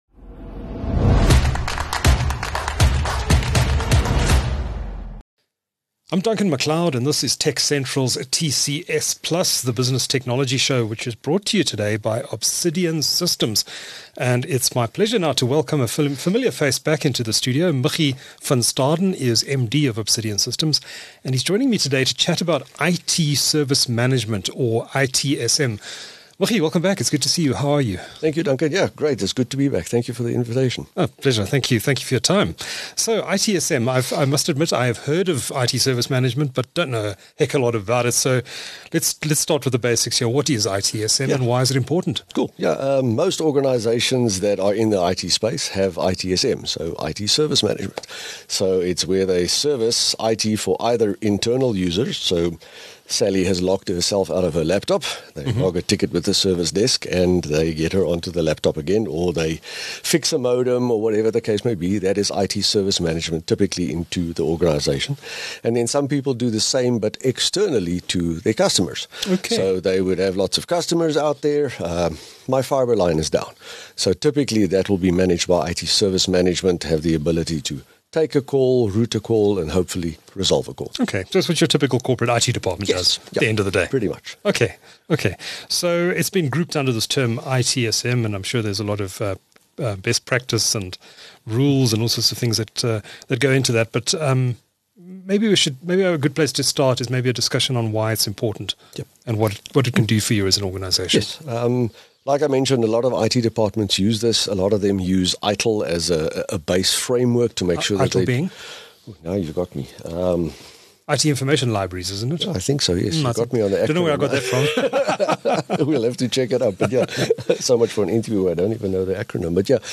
Don’t miss a fascinating conversation with a leader in the ITSM space in South Africa.